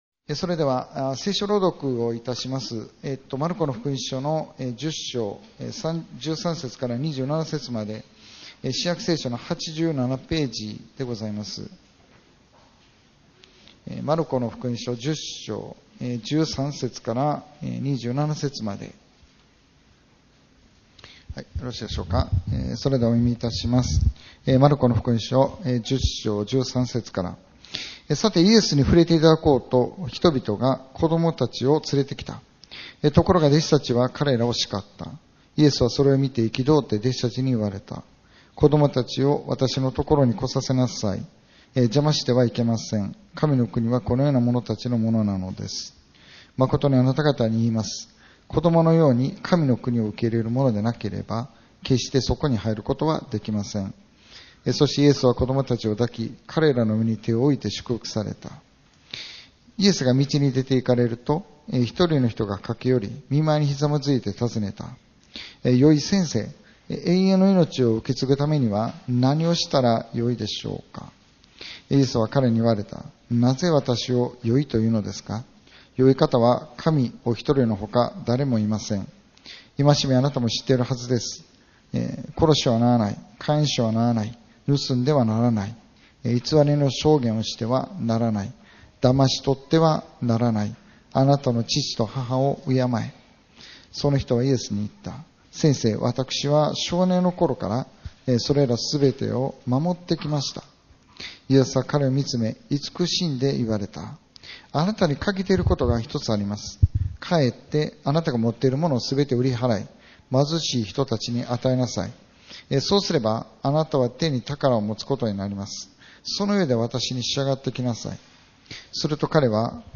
2025年9月28日礼拝説教「だれが救われることができるでしょう」 | 生田丘の上キリスト教会